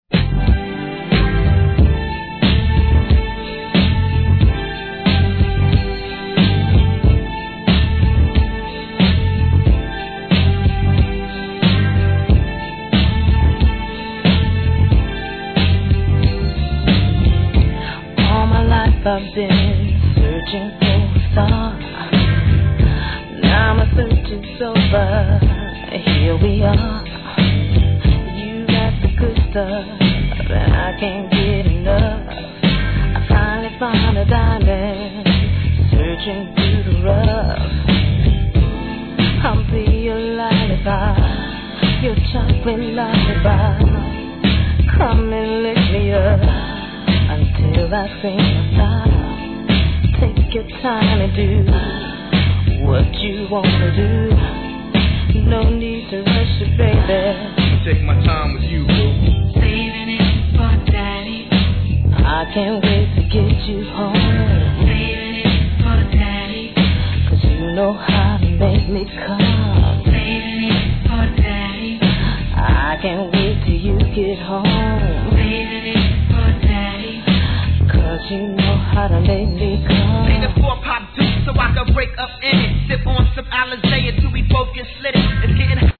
HIP HOP/R&B
RAPのいなたい絡みも◎